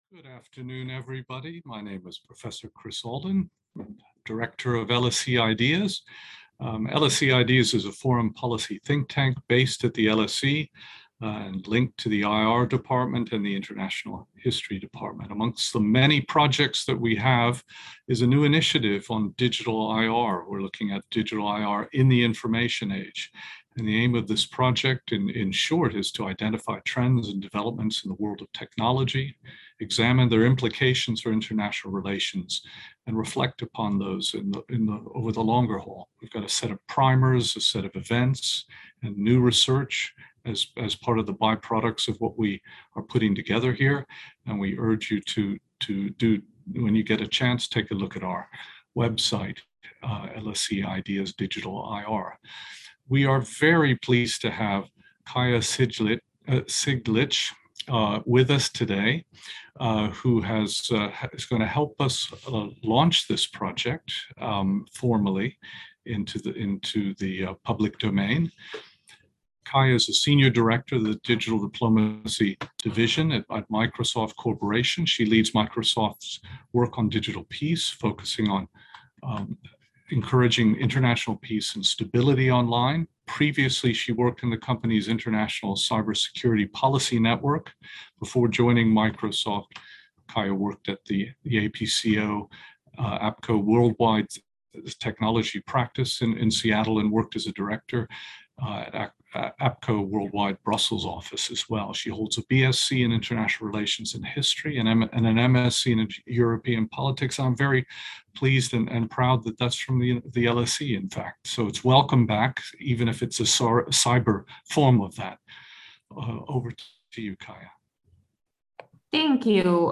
Watch: Cyber Norms Listen: Cyber Norms This webinar was held on Thursday 10 February 2022.